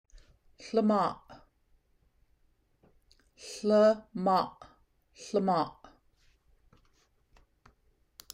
[h-lim-awhh] “to help” in Simalgyax
hlimoo-pronunciation.m4a